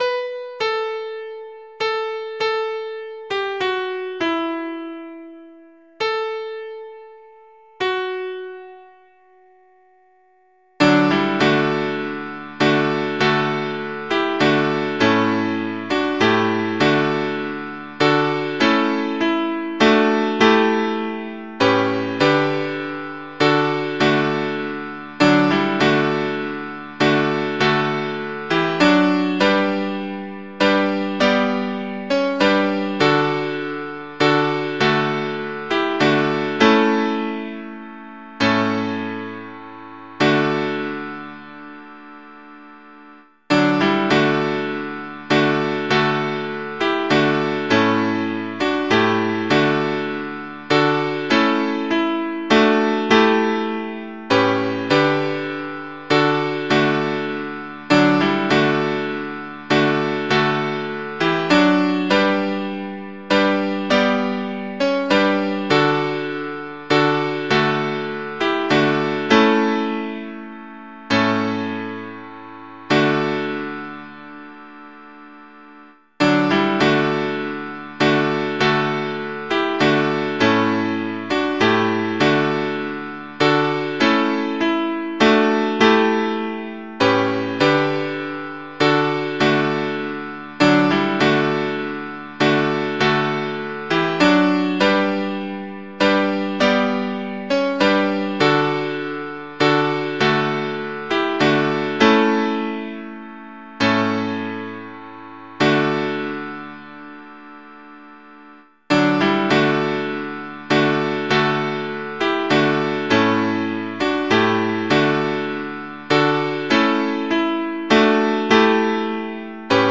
Mp3 Audio of Tune Abc source